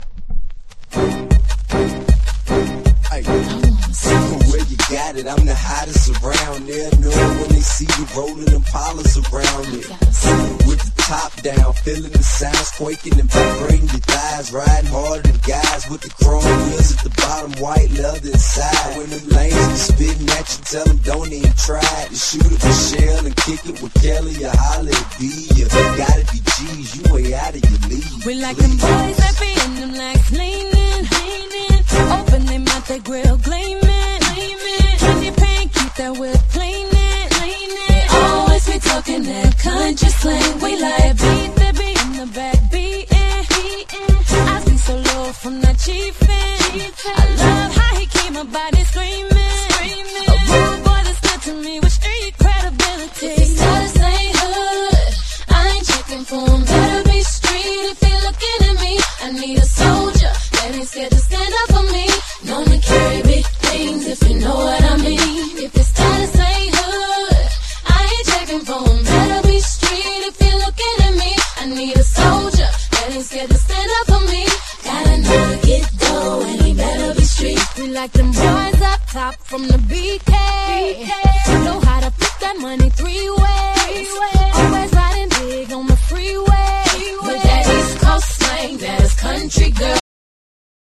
迫力の重低音とハンドクラップが織り成すダンス･ナンバー！